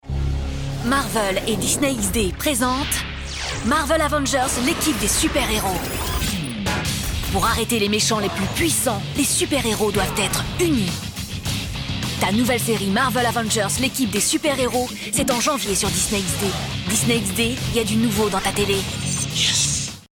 Catégories : Bandes Annonces, Extraits Audio | Mots-clés : Avengers, BA, Bandes Annonces, Channel, Convaincant, Disney XD, Marvel, Promo, Punchy, TV | Permaliens